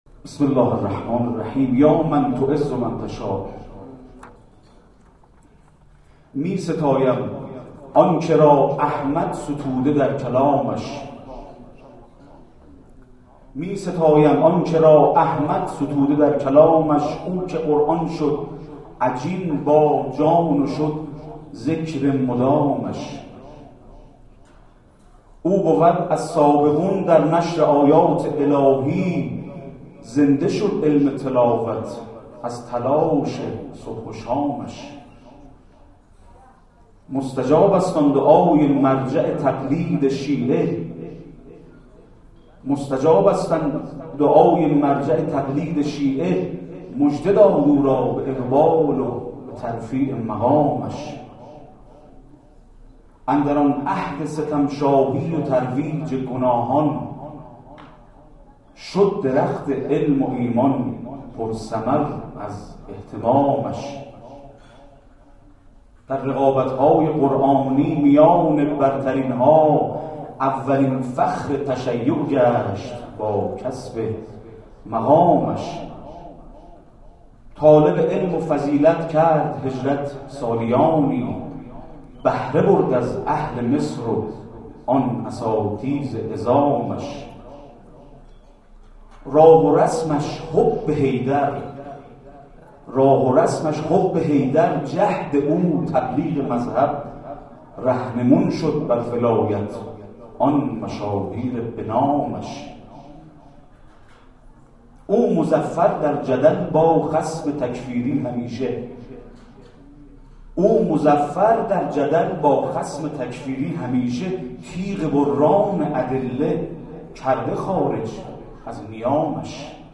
در مسجد محمدیه واقع در حوالی میدان حسن آباد تهران برگزار شد.
شعرخوانی